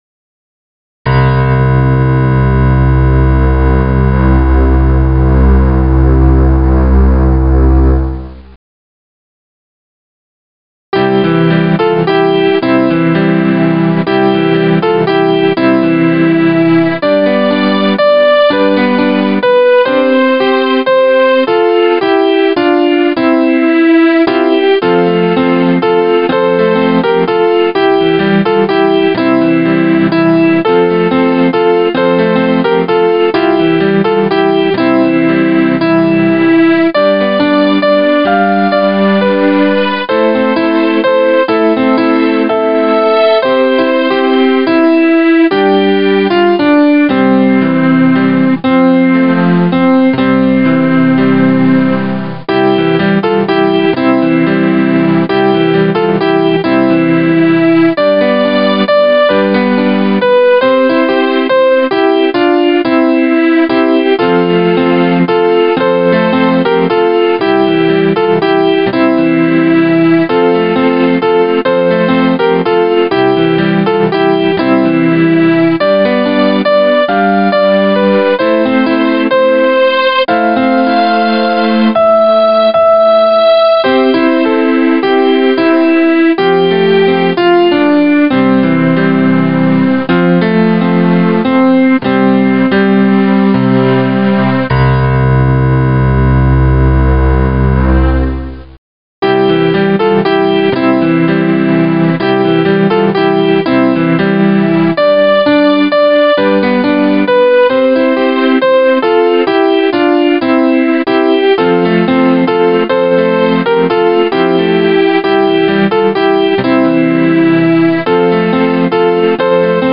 keyboard
Voice used: Grand piano
Tempo: Calmly Music written by Franz Gruber 1818